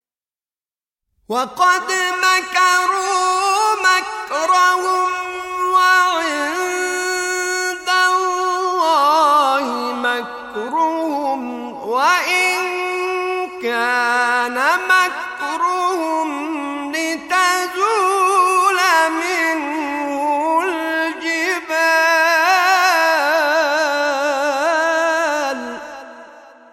مقام نهاوند نوا یا درجه پنجم نهاوند